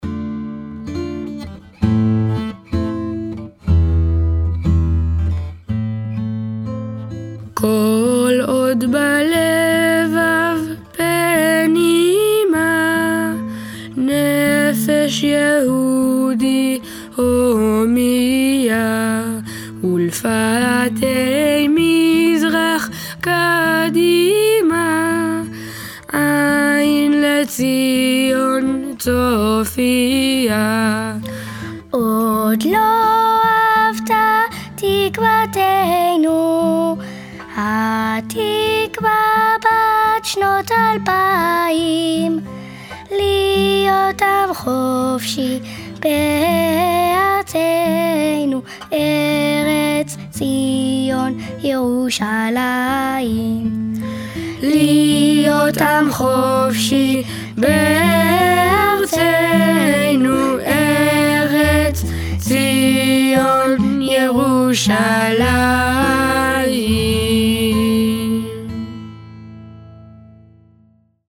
Audio Enfants: